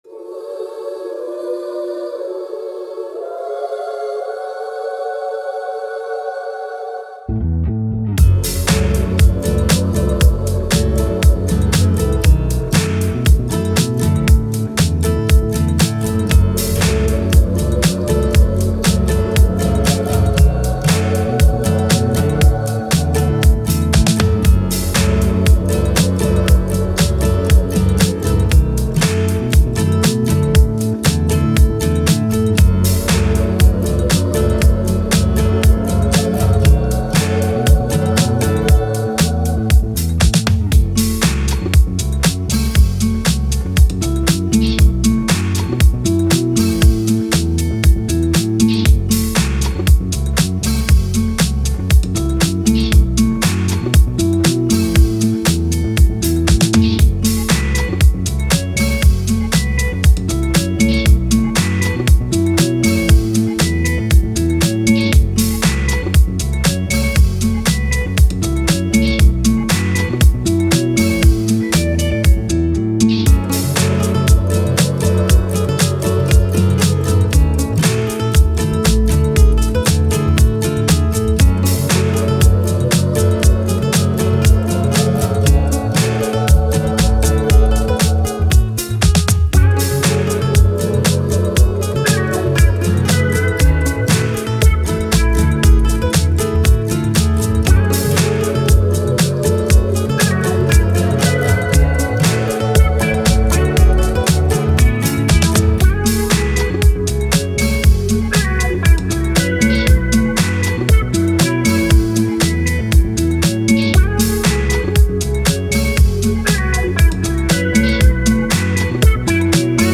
Dance, Choir, Upbeat, Funky